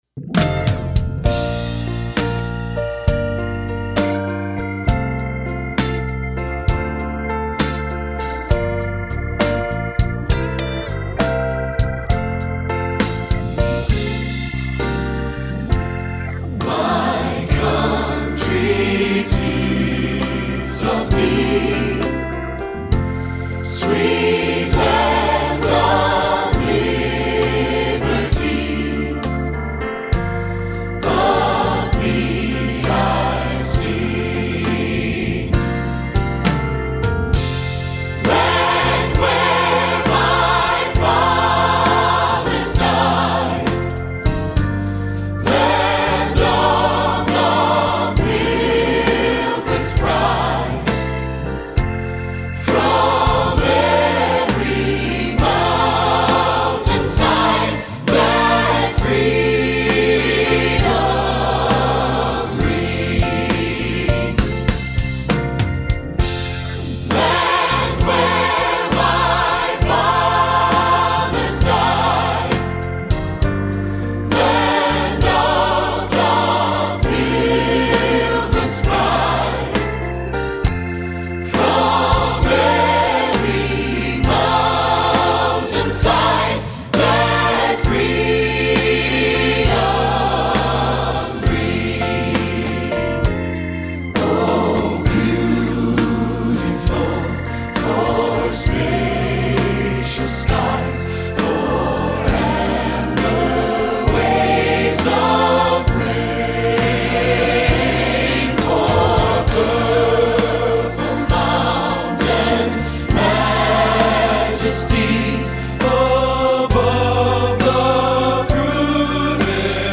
For 3 Part Mixed, Optional Baritone.
3 Part Mix